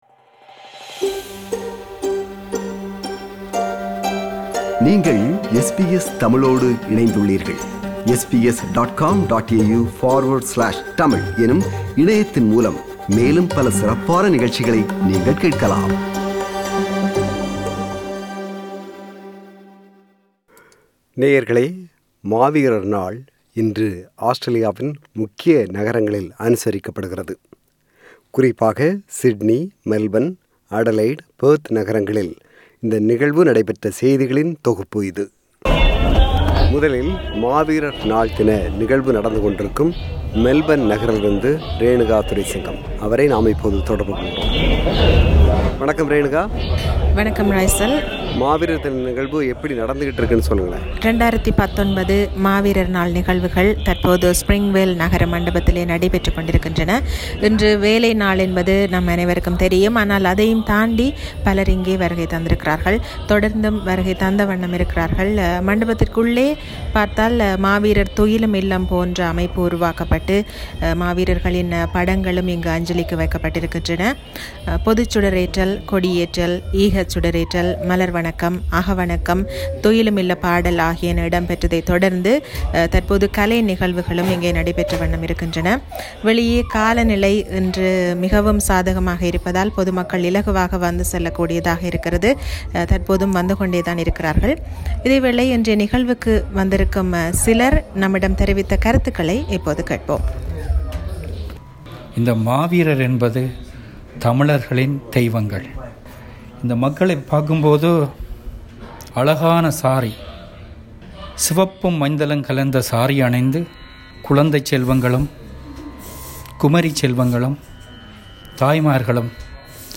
Maaveerar Naal – A report from Melbourne
Maaveerar Naal is observed all over the world to remember the deaths of Tamil people died during the civil war in Sri Lanka since 1982. This is a report from Melbourne where Maaveerar Naal is observed today (27 Nov 2019).